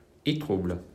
Étroubles (French: [etʁubl]
Fr-Étroubles.mp3